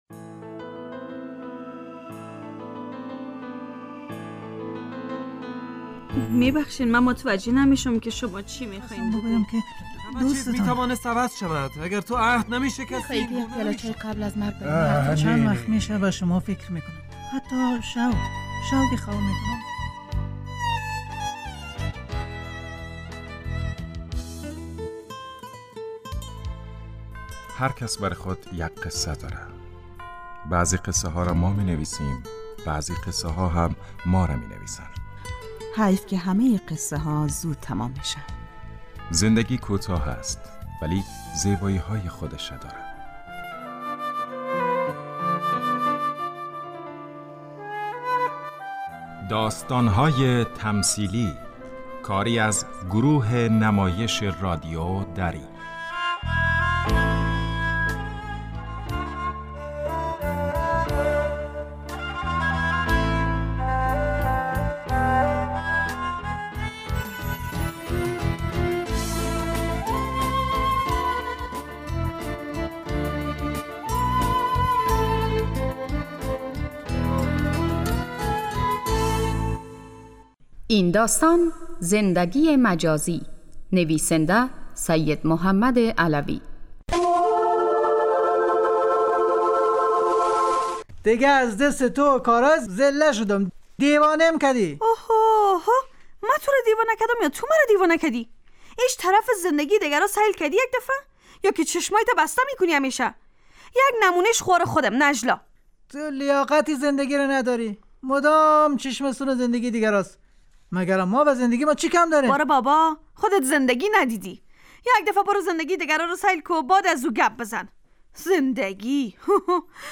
داستان تمثیلی - زندگی مجازی